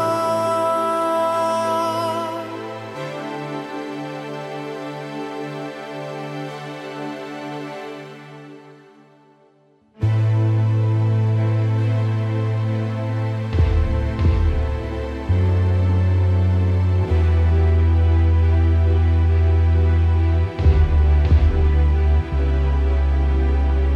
One Semitone Down Rock 4:35 Buy £1.50